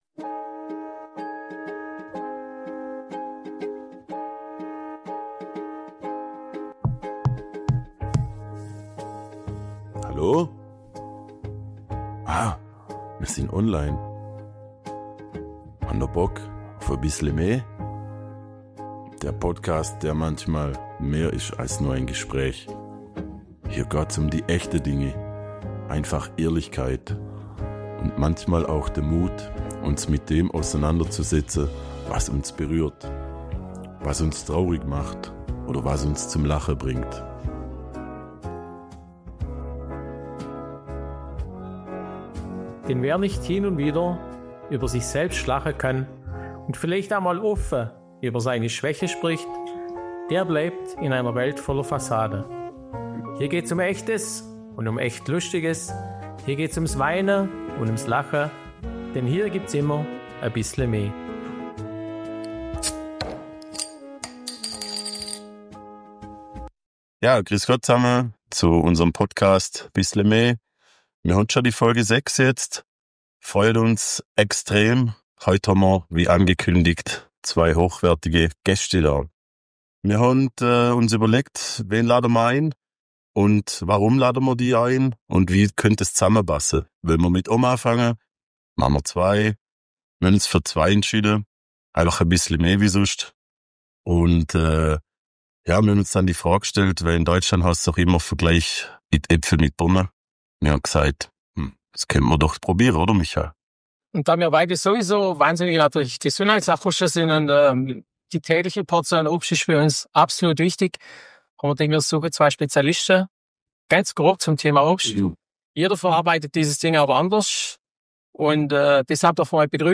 In dieser Folge wird nicht lang gefackelt – es wird gepresst, gebrannt und gelacht!